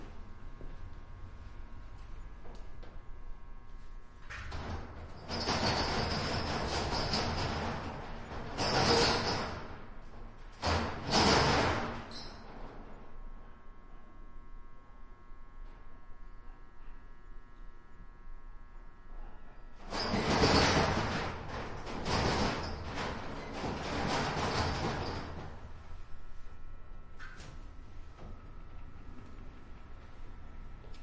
随机 " 门大型车库工业滑开和关闭的中等视角回声纽约MS
Tag: 打开 关闭 幻灯片 车库 工业